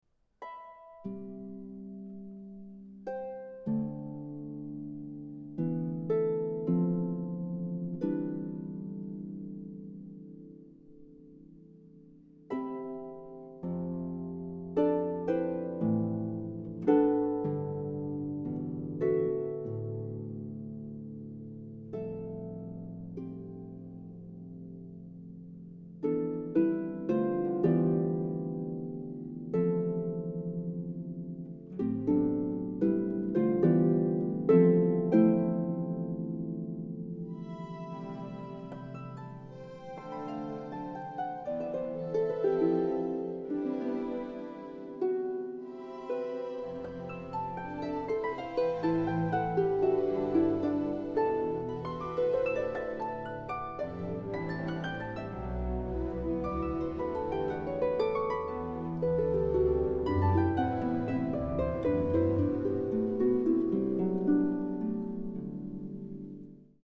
Instrumentation: harp soloist and orchestra